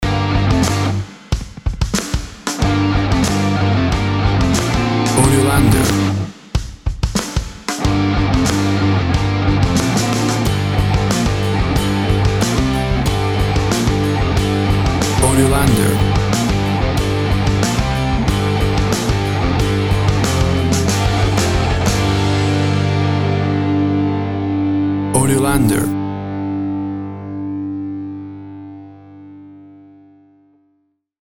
1970´s Classic heavy metal Rock.
Tempo (BPM) 93